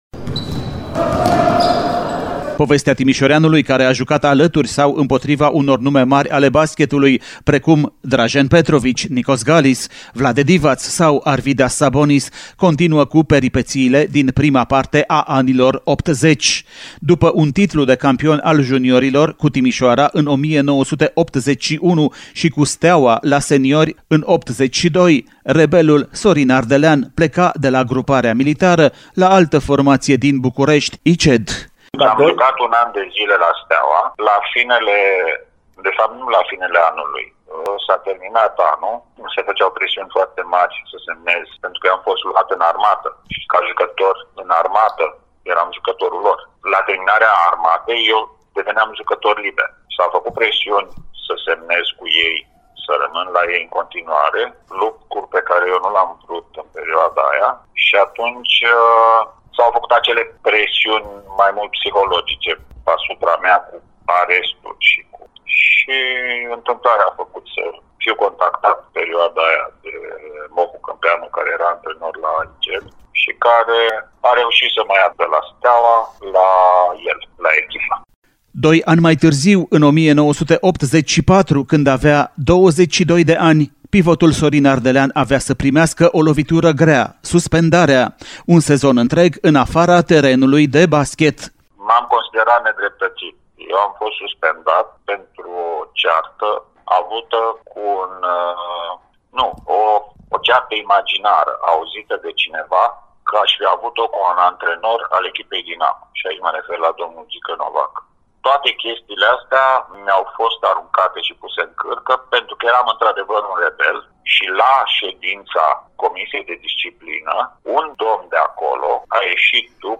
Reportajul cu declarații AUDIO pe larg